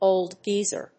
old+geezer.mp3